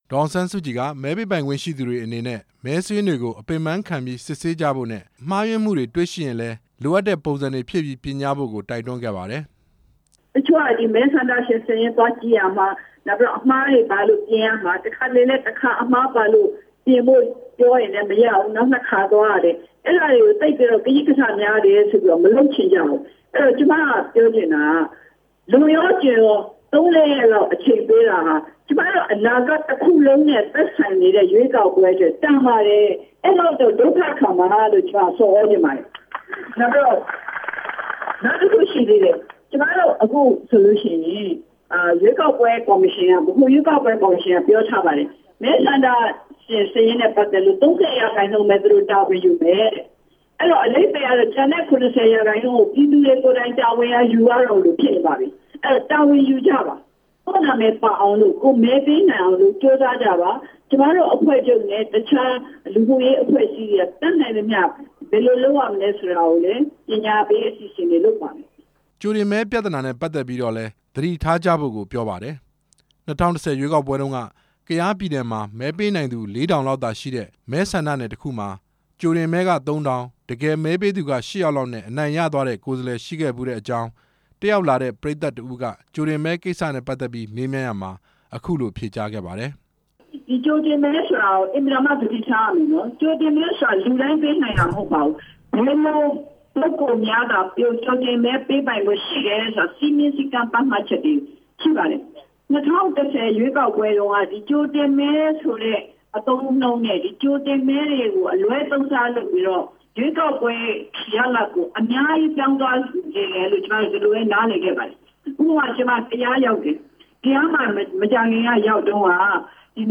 ဒေါ်အောင်ဆန်းစုကြည်ရဲ့ ဟုမ္မလင်းမြို့ ဟောပြောပွဲ တင်ပြချက်